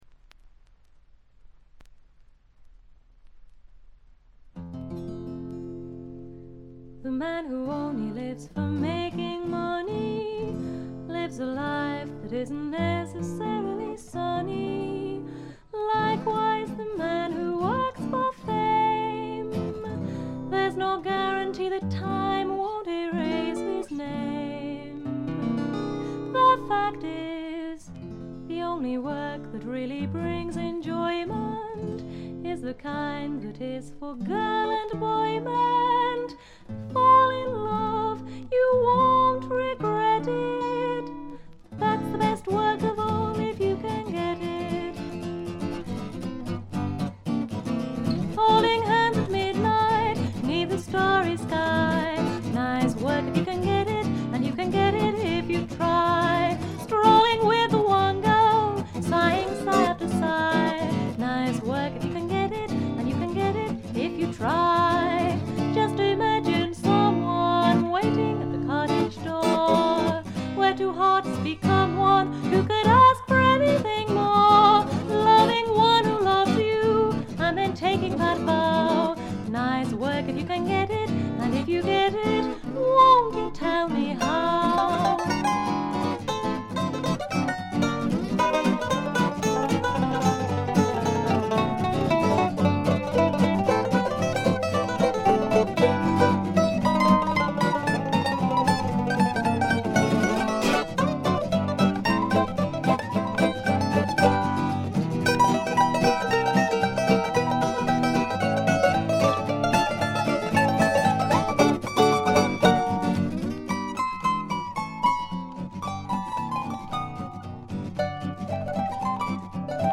軽微なチリプチ少しだけ。
ほとんどこの二人だけで演奏しておりかなりの腕達者です。
オールドタイミーなグッタイム・ミュージック好きな方やフィメールものがお好きな方ならばっちりでしょう。
試聴曲は現品からの取り込み音源です。